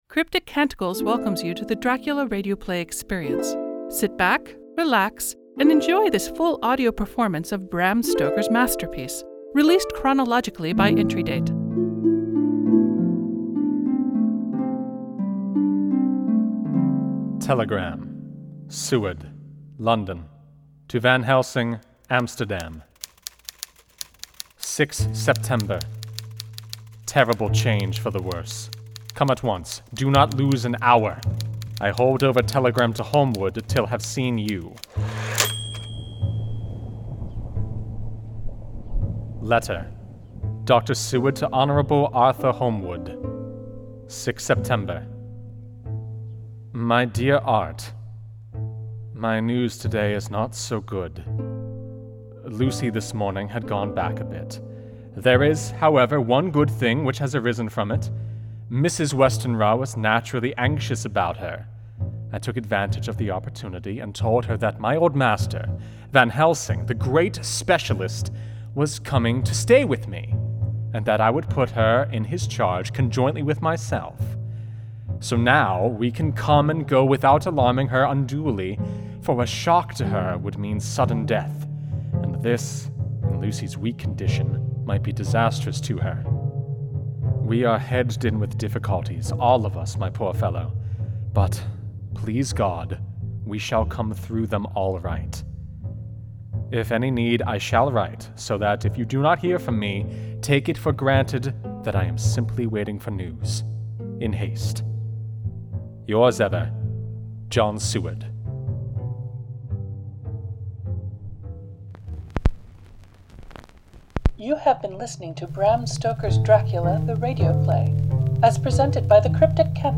Audio Engineer, SFX and Music
This is a rebroadcast of the original 2017 work.